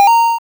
Pickup_token.wav